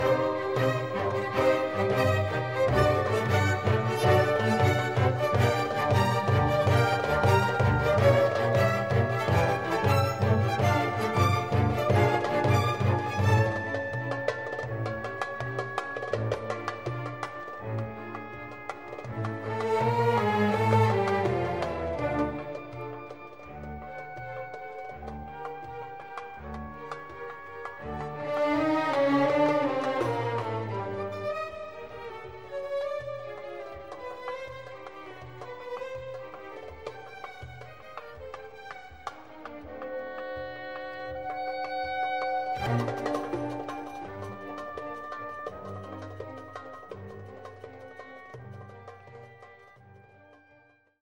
Respiration musicale :